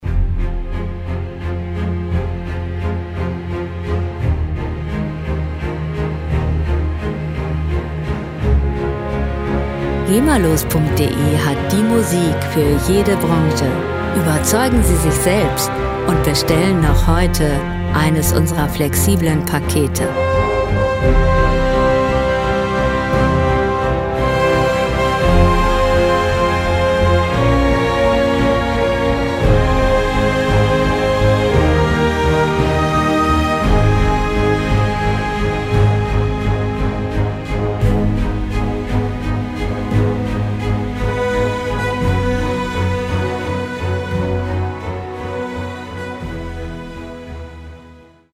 Klassik Pop
Musikstil: Crossover
Tempo: 57 bpm
Tonart: F-Moll
Charakter: dramatisch, cineastisch
Instrumentierung: Sinfonieorchester